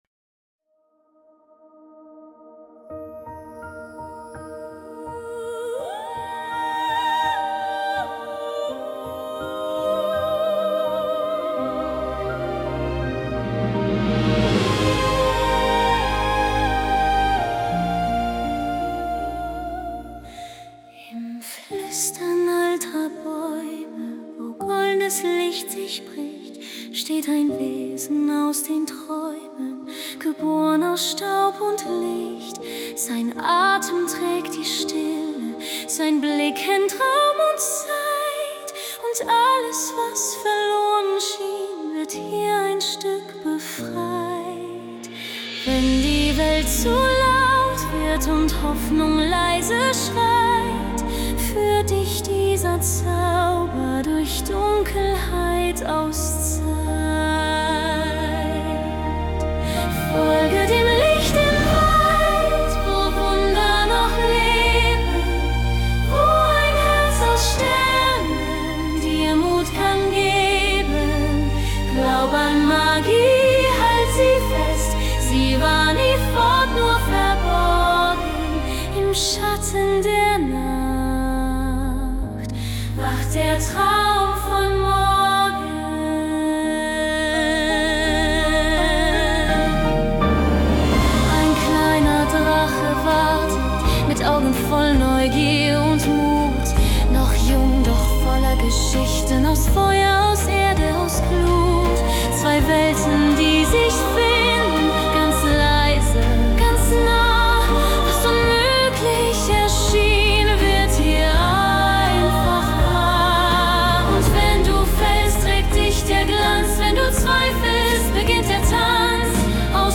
Goldene Klänge und leise Fantasie begleiten dich.